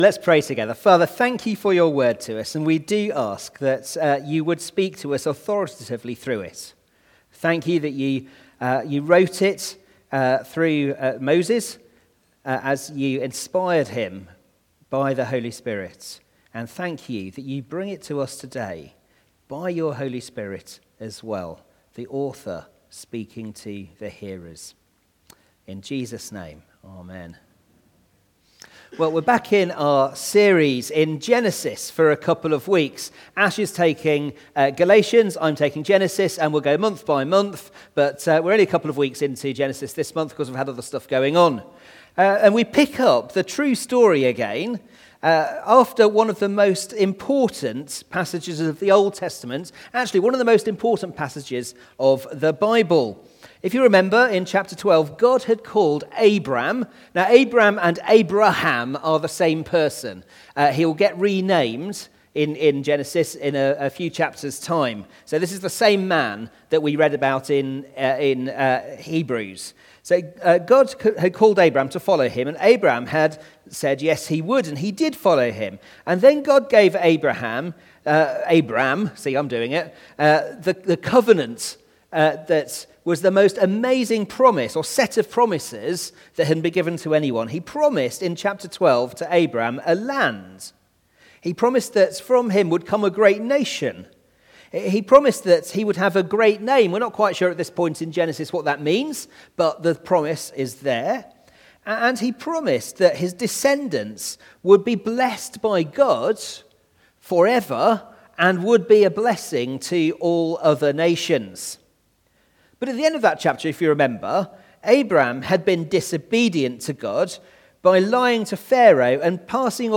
Media Library We record sermons from our Morning Prayer, Holy Communion and Evening services, which are available to stream or download below.
Passage: Genesis 13:1-18 Series: The Gospel for the Nations Theme: Sermon Search